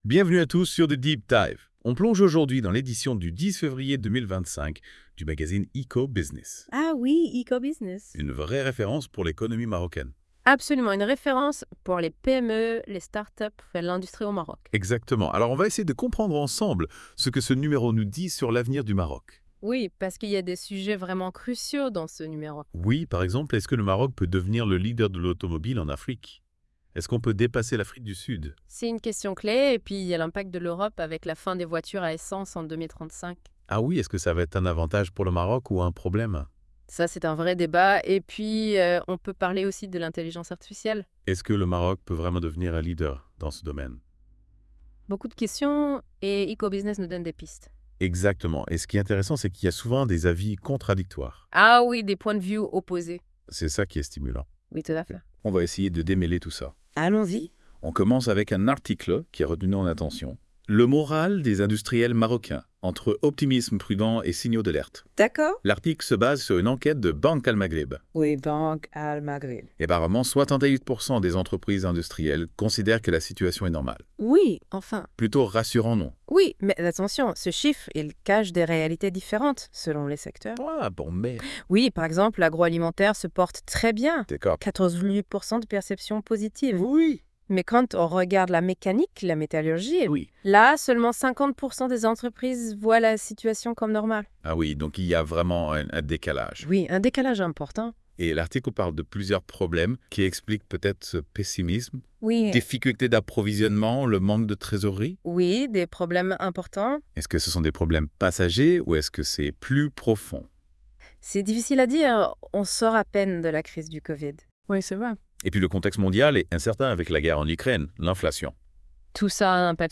Débat (36.26 Mo) Quel est le moral des industriels marocains selon l’enquête de la BAM ?